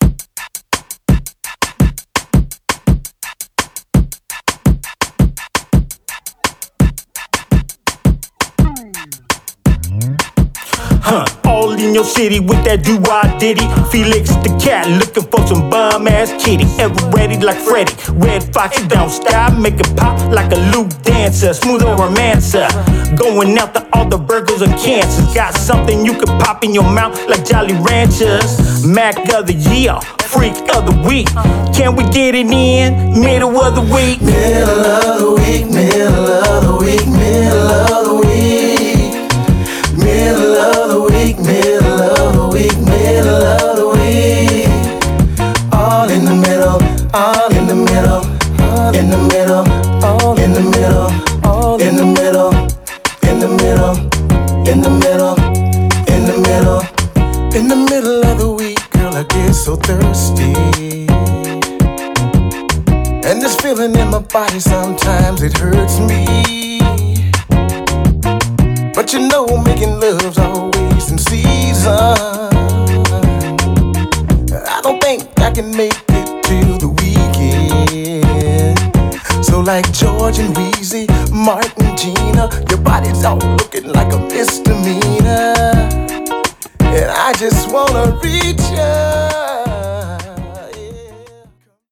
Mastering Samples